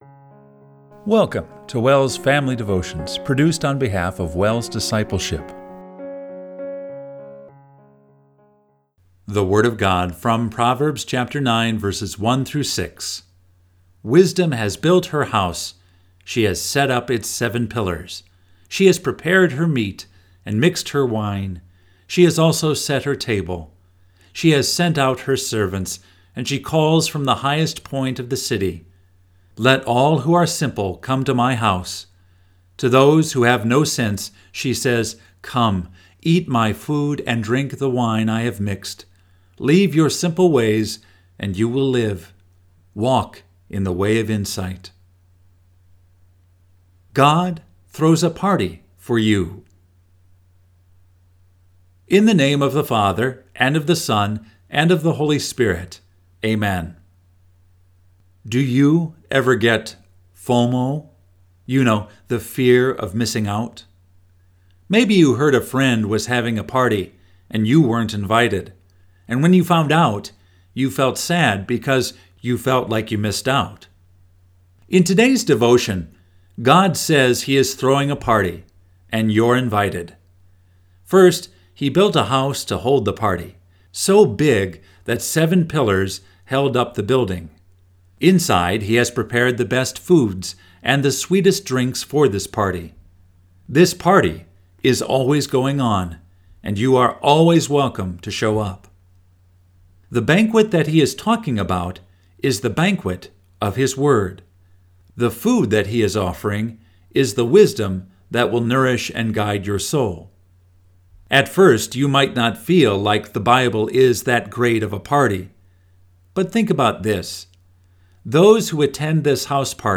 Family Devotion – August 19, 2024